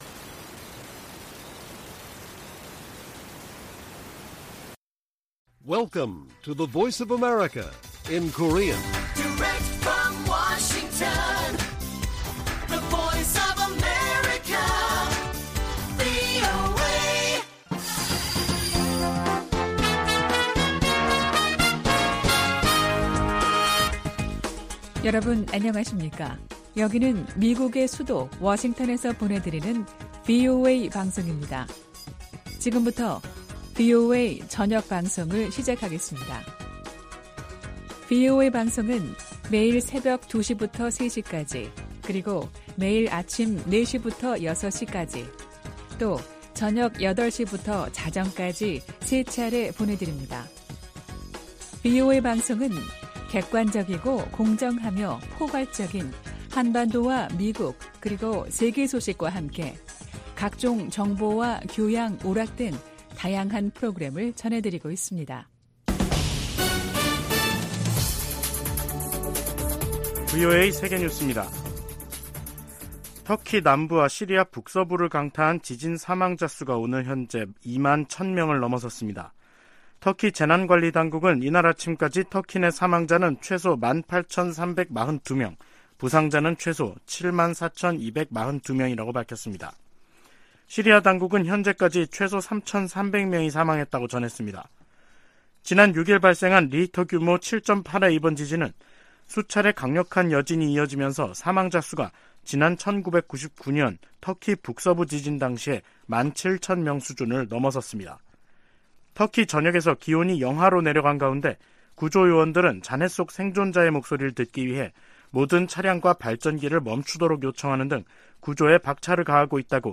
VOA 한국어 간판 뉴스 프로그램 '뉴스 투데이', 2023년 2월 10일 1부 방송입니다. 한국 정부가 사이버 분야에 첫 대북 독자 제재를 단행했습니다. 미국 국무부는 북한이 건군절 열병식을 개최하며 다양한 무기를 공개한 상황에서도 한반도 비핵화 목표에 변함이 없다고 밝혔습니다.